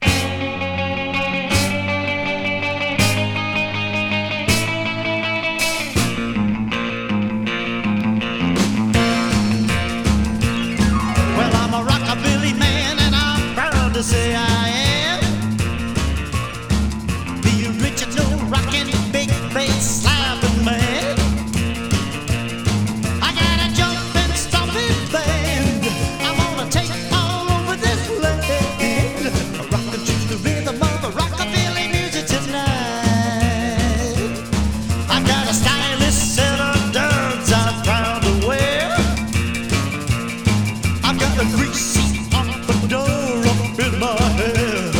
レーベルを代表するメンツの個性炸裂、ロウでゴリゴリの演奏録音が最高。
Rock'N'Roll, Rockabilly　USA　12inchレコード　33rpm　Stereo